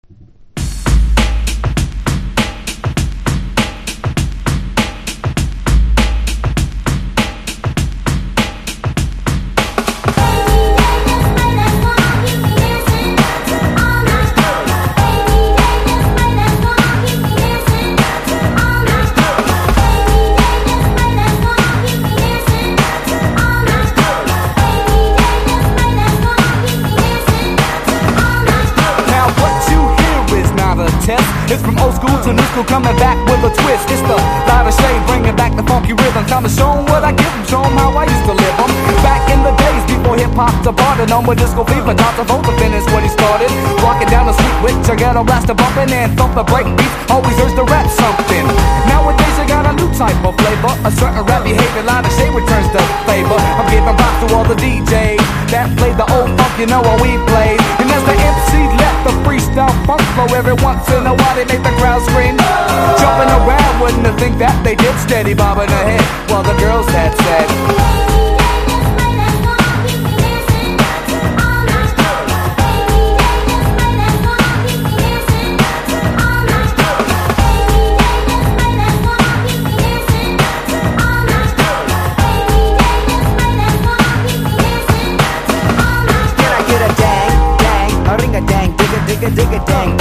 90’S HIPHOP